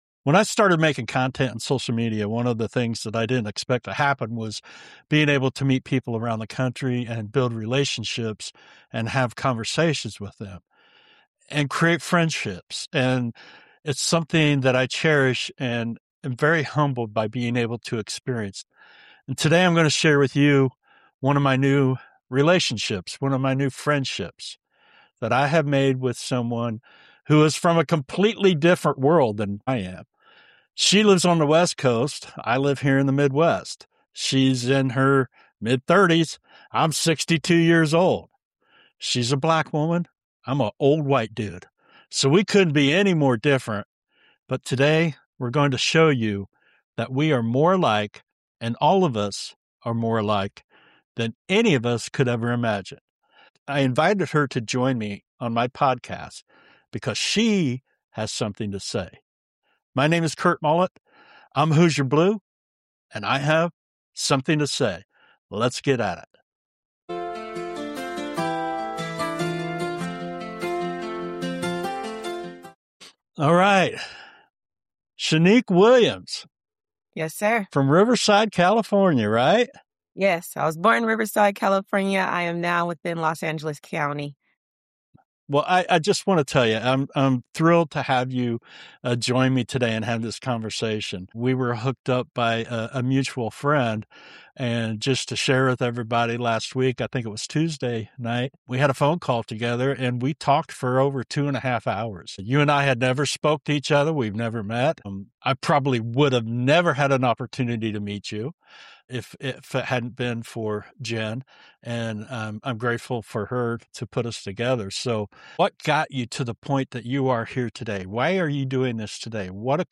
For the first time with my podcast, I invited a guest to have a conversation with me.